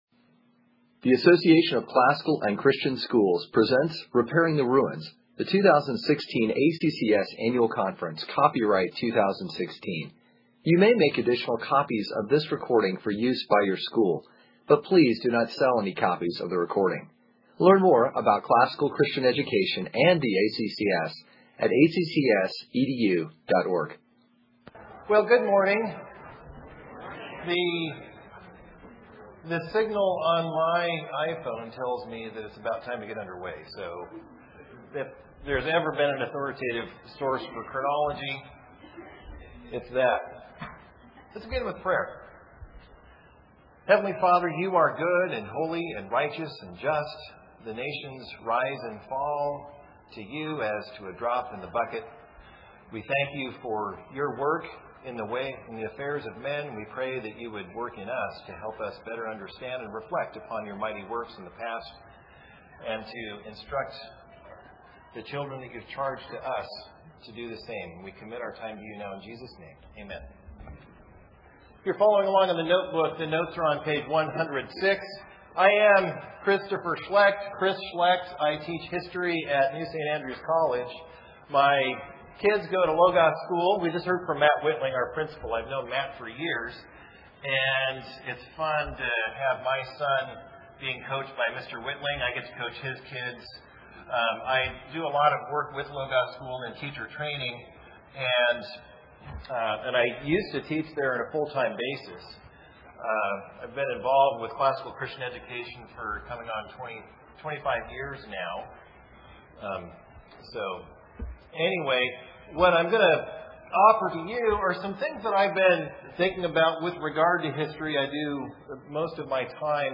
2016 Workshop Talk | 0:59:08 | All Grade Levels, History
Additional Materials The Association of Classical & Christian Schools presents Repairing the Ruins, the ACCS annual conference, copyright ACCS.